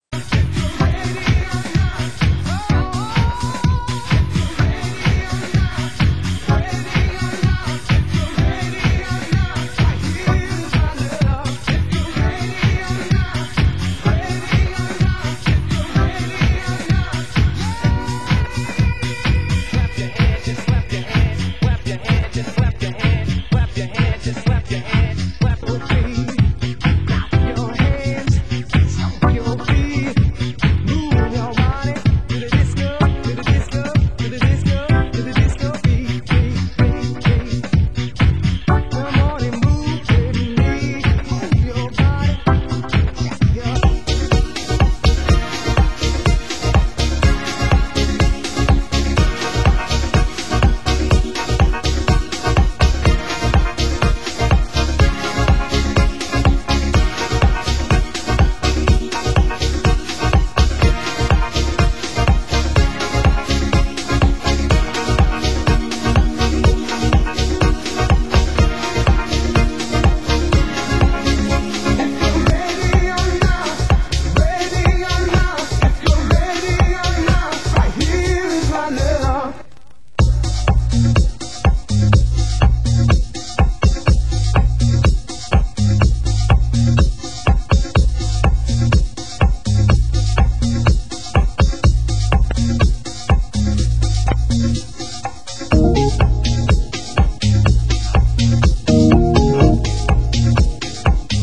AccueilElectro / New grooves  >  Deep House